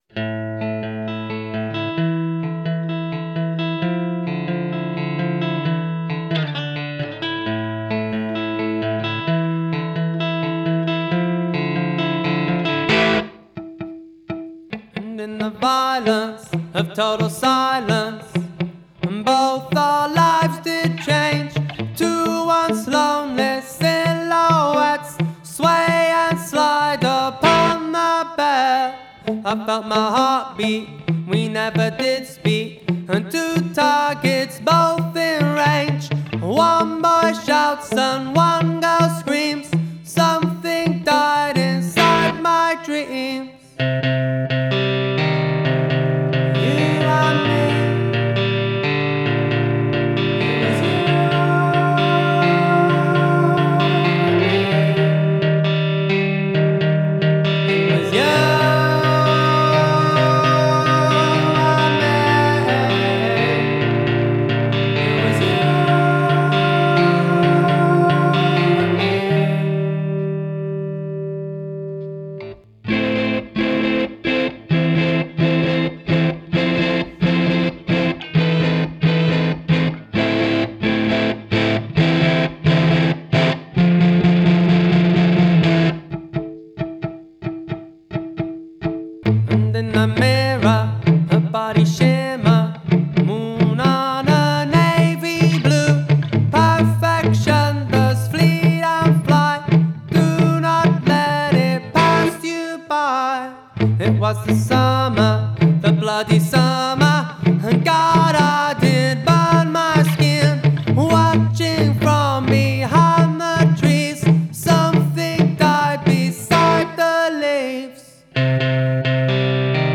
guitar
A quiet racket.